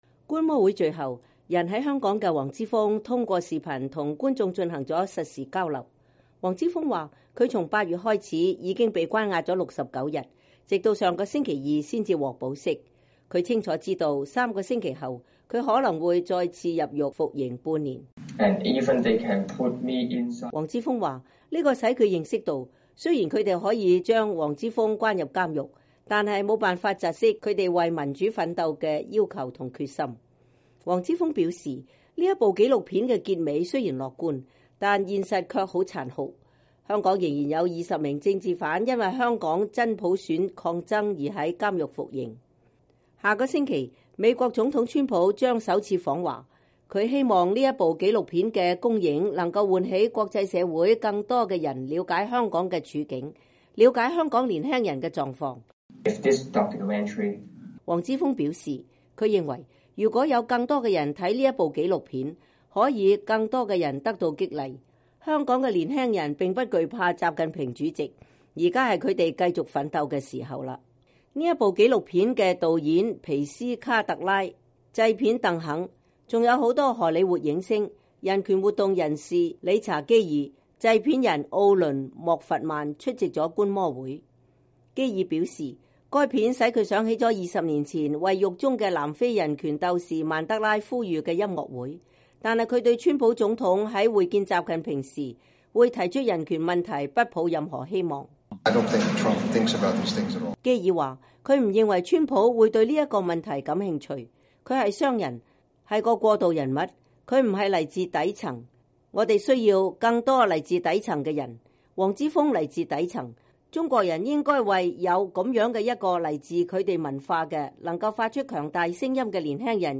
觀摩會最後，人在香港的黃之鋒通過Skype跟觀眾進行了實時交流。